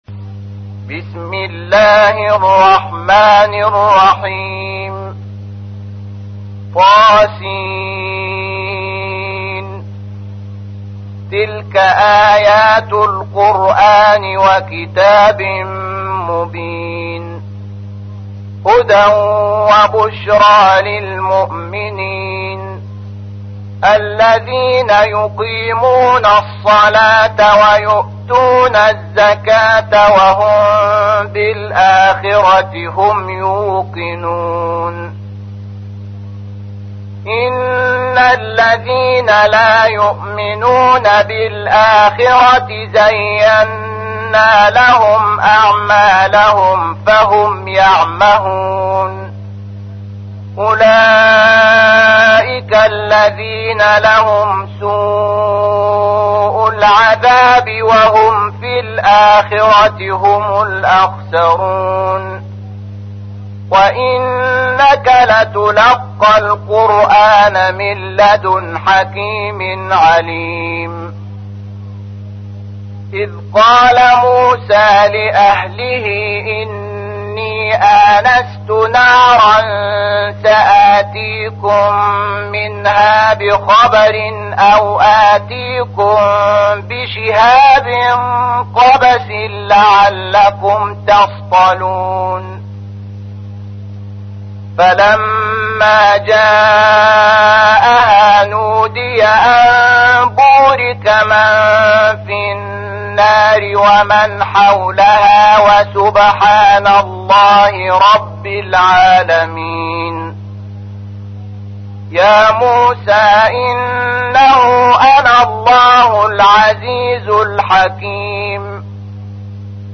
تحميل : 27. سورة النمل / القارئ شحات محمد انور / القرآن الكريم / موقع يا حسين